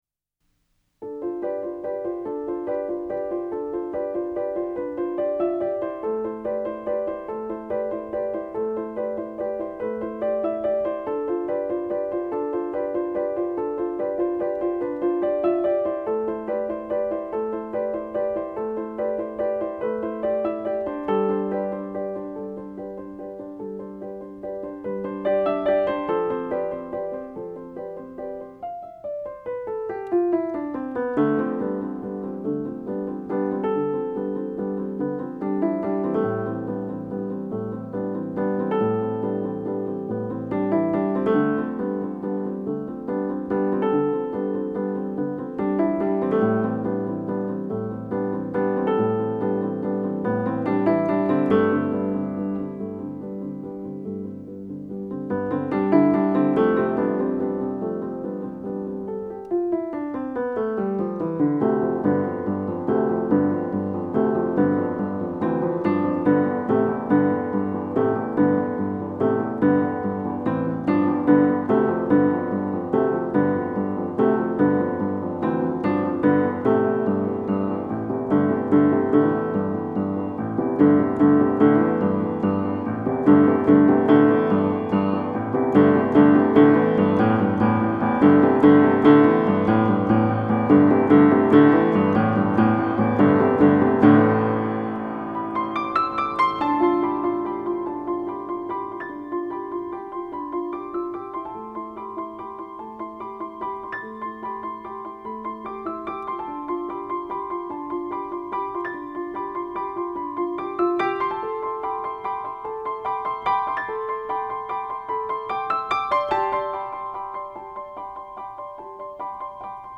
a talented pianist with a seasonally appropriate name.